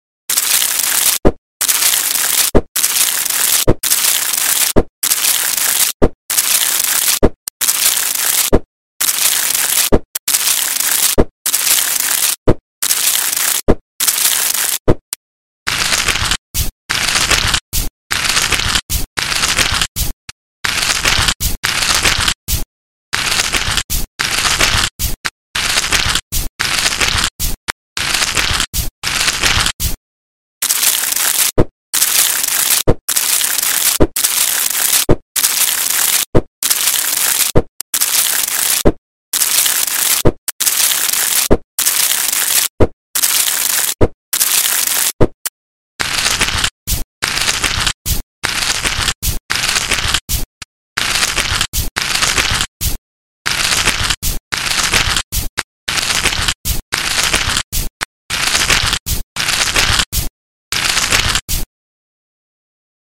routine of relaxing foot care sound effects free download
ASMR perfect relief for tried feet